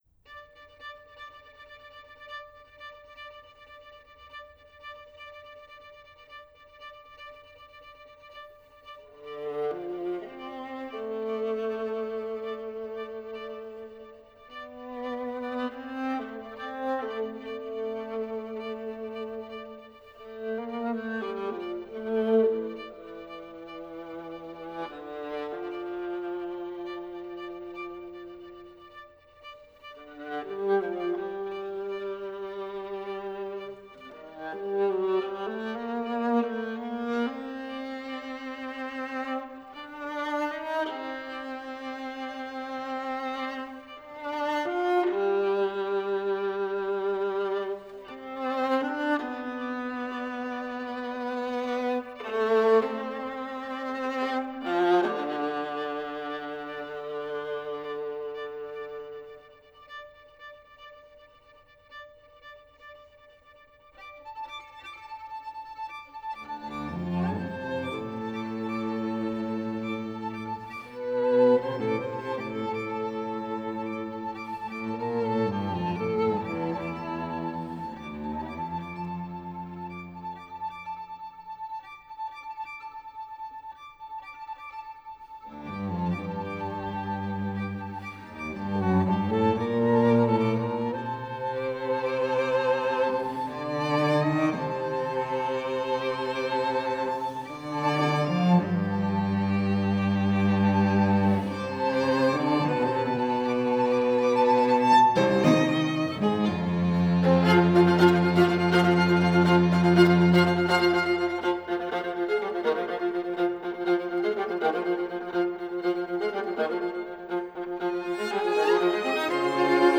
Instrumentation: string quartet